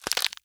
BREAK_Crack_stereo.wav